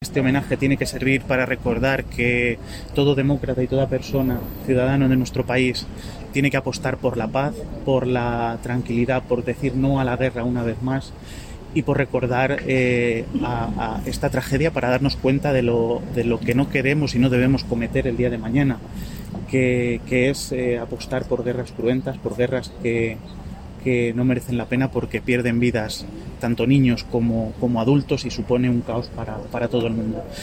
Declaraciones del alcalde en el homenaje del 11M (1)